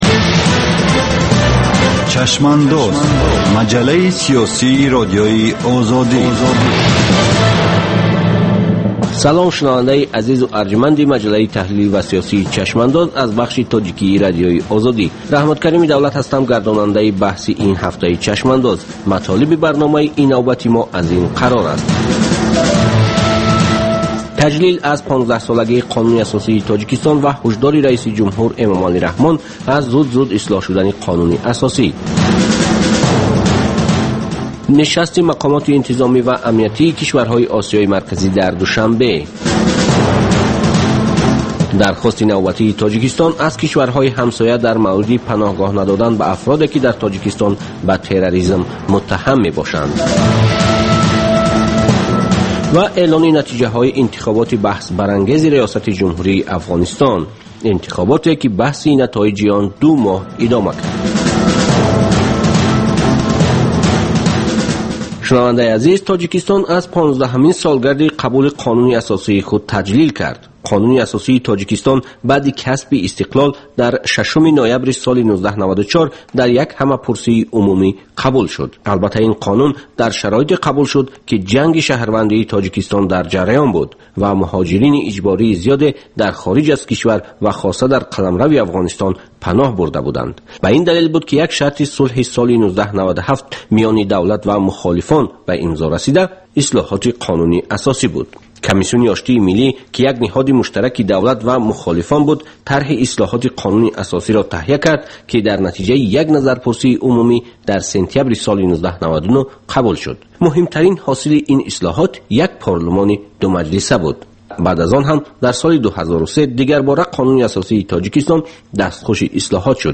Баррасӣ ва таҳлили муҳимтарин рӯйдодҳои сиёсии рӯз дар маҷаллаи "Чашмандоз". Гуфтугӯ бо коршиносон, масъулини давлатӣ, намояндагони созмонҳои байналмилалӣ.